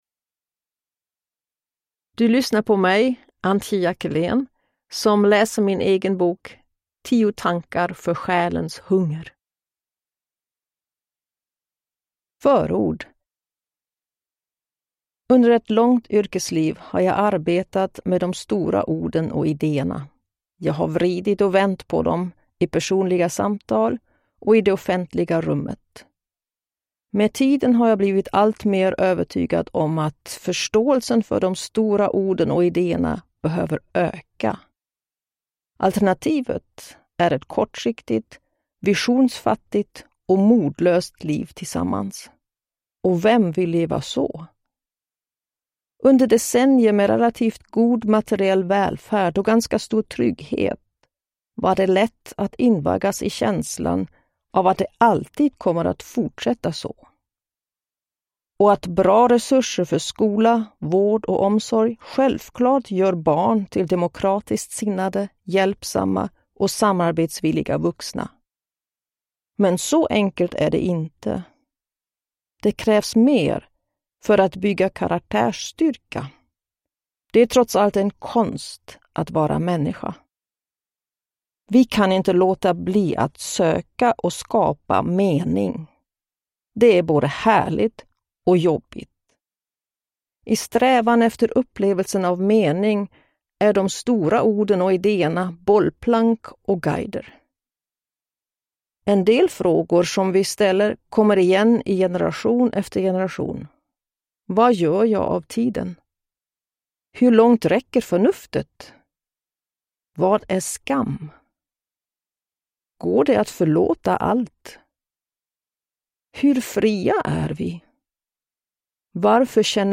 Downloadable Audiobook
Ljudbok
Narrator
Antje Jackelén